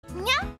Play, download and share Nyaa original sound button!!!!
nyaa-1.mp3